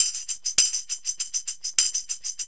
TAMB LP 100.wav